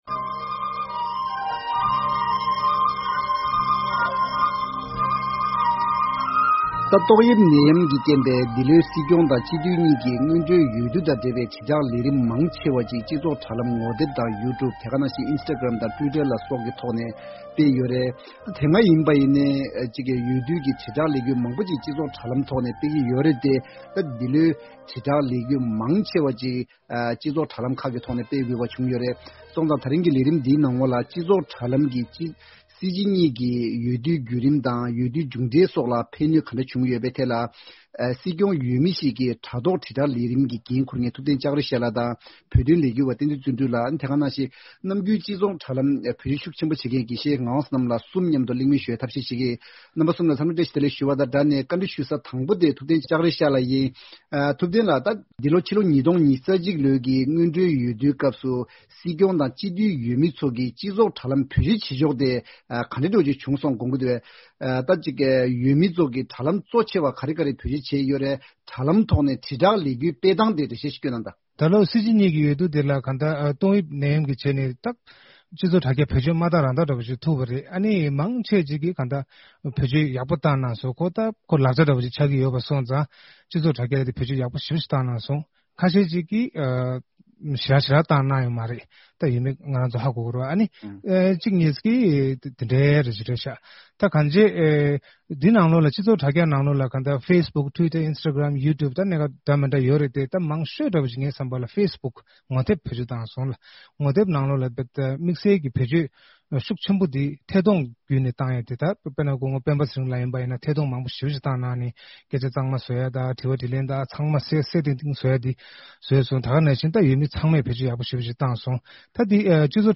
ད་རིང་གི་བགྲོ་གླེང་མདུན་ཅོག་ལས་རིམ་ནང་འོས་བསྡུའི་སྐབས་སུ་སྤྱི་ཚོགས་དྲྭ་རྒྱ་བཀོལ་སྦྱོད་བྱེད་ཕྱོགས་བྱེད་ཕྱོགས་ལ་འོས་མི་དང་འོས་འཕེན་མི་མང་གཉིས་ཀྱིས་གཟབ་ནན་བྱ་དགོས་པ་གང་ཡོད་པ་སོགས་ཀྱི་ཐད་ལ་གླེང་མོལ་ཞུ་ཡི་ཡིན།